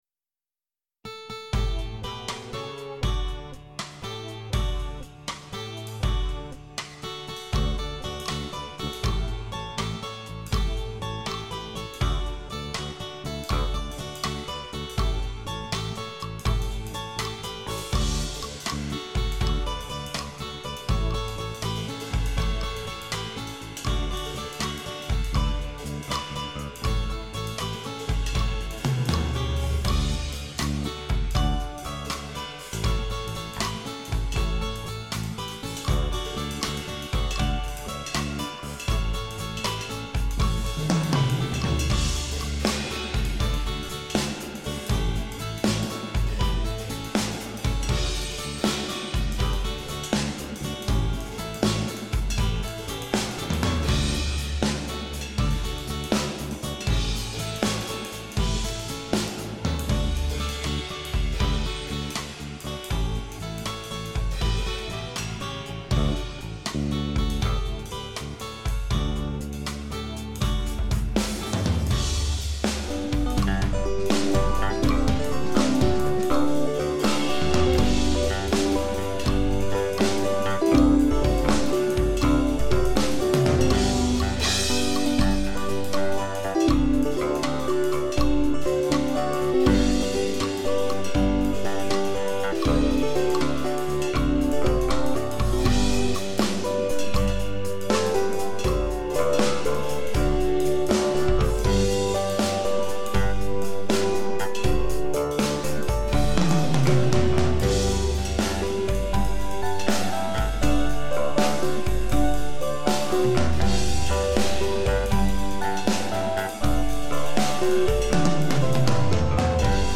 drums, perc, ac-guitar, e-bass, keyboard, voice
recorded with Lexicon Omega and Cubase4
Den Bass habe ich analog eingespielt.
The e-bass I played unplugged.